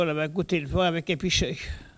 collecte de locutions vernaculaires